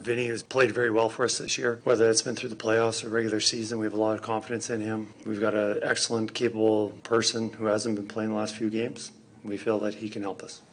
Head coach Kris Knoblaugh spoke about Vinny being back on the ice:
knoblaugh-on-vinny-coming-back-in.mp3